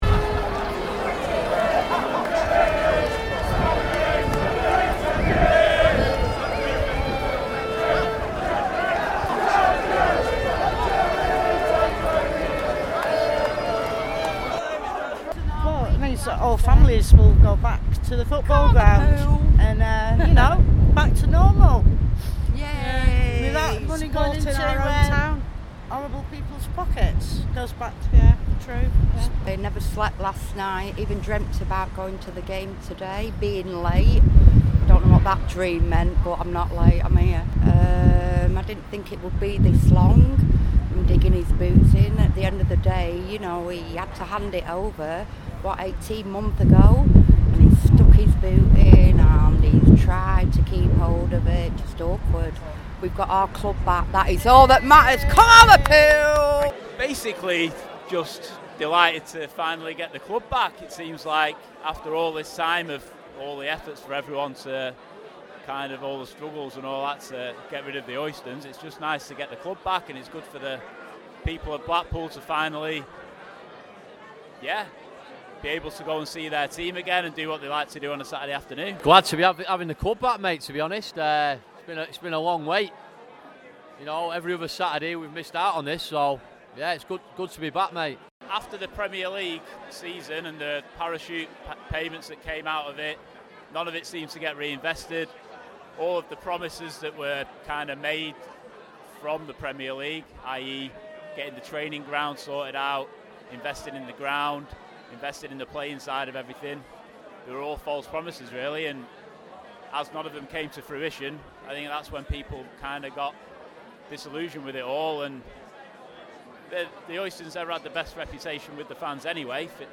Nearly two years after we recorded the first Who Are Ya, we joined the Blackpool fans again - as the Bloomfield Road turnstiles welcomed back so many old friends. Here is a short episode, where they tell us in their own words, what it means to be going home.
Interviews